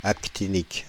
Ääntäminen
France (Île-de-France): IPA: /ak.ti.nik/